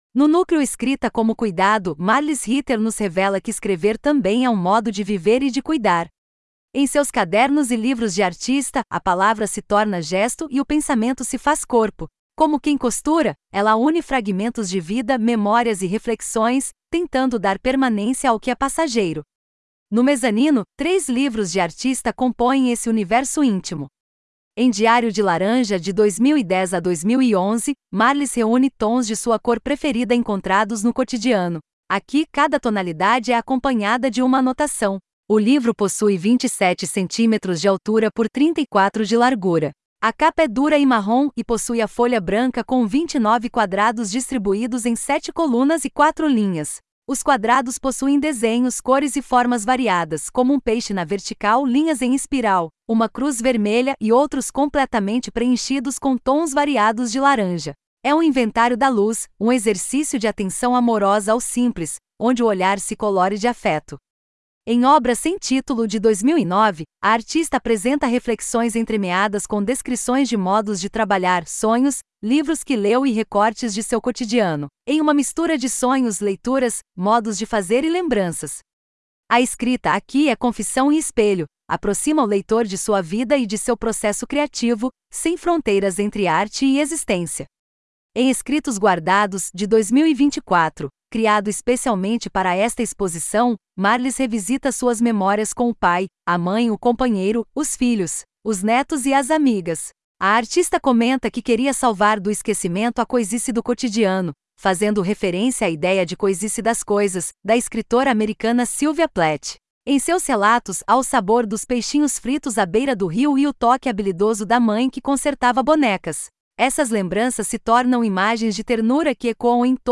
Audiodescrição do Núcleo 2 | Escrita como Cuidado